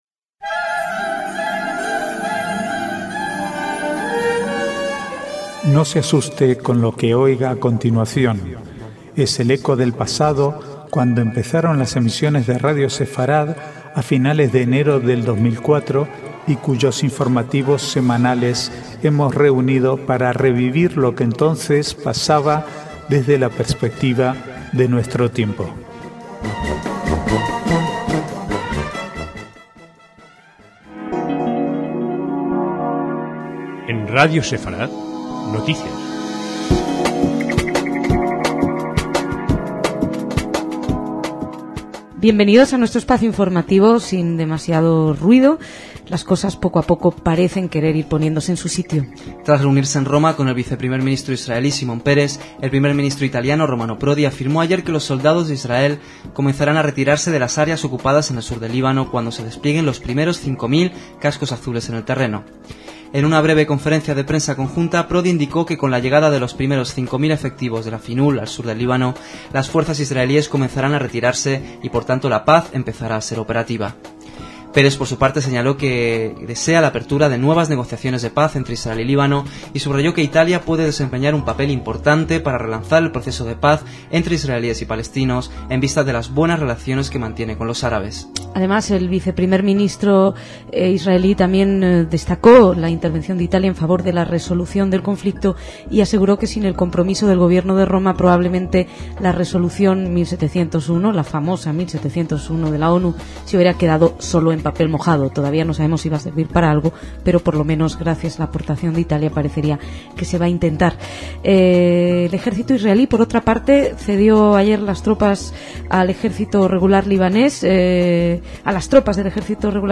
Archivo de noticias del 1 al 6/9/2006